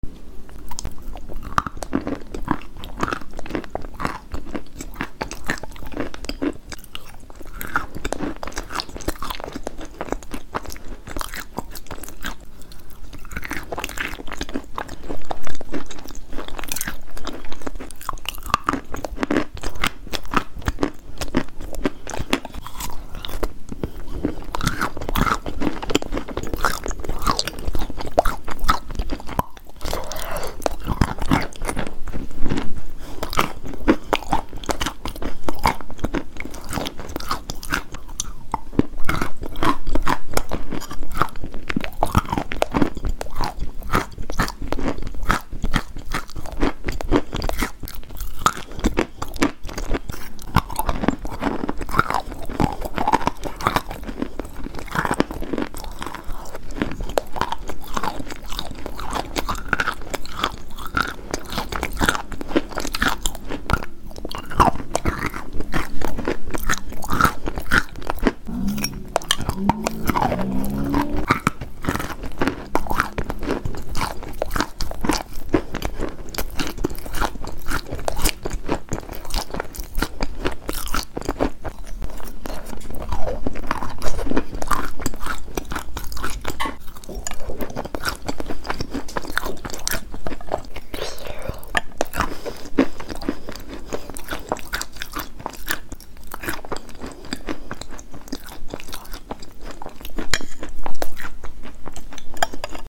ASMR | Soft Orange Cookies 🍊🤤🤤